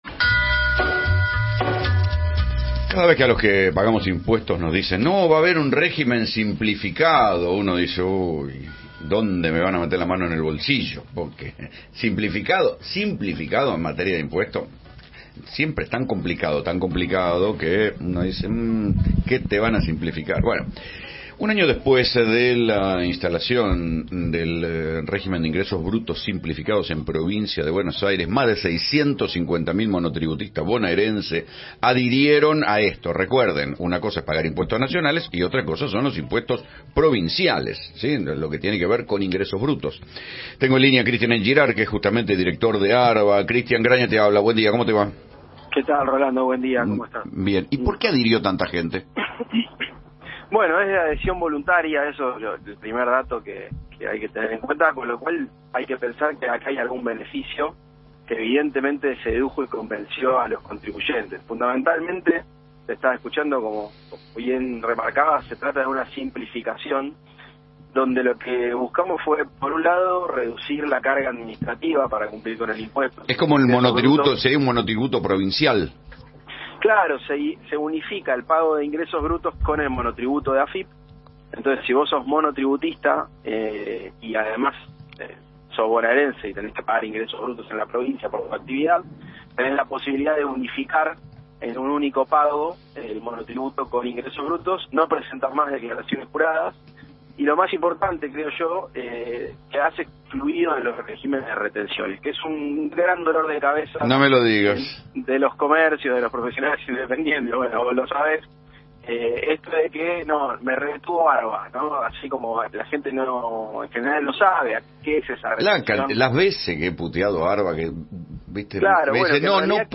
Entrevista-a-Cristian-Girard-en-dialogo-con-Rolando-Grana.mp3